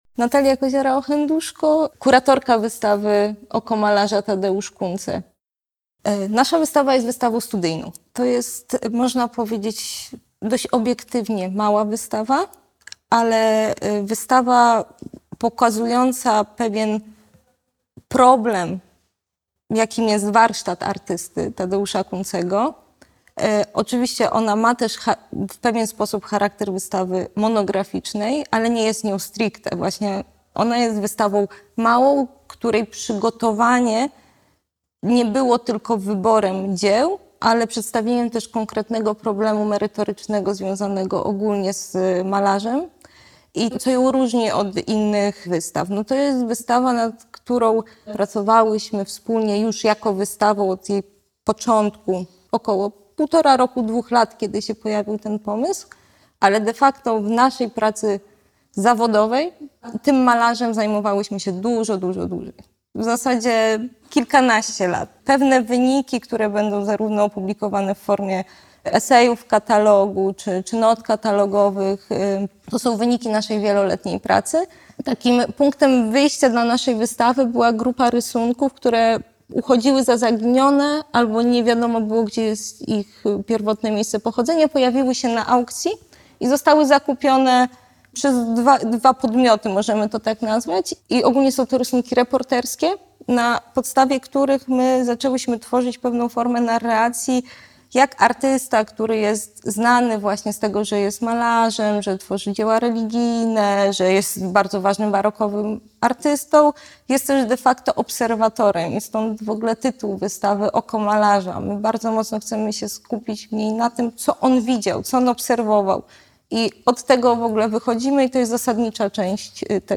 O ekspozycji opowiadają kuratorki wystawy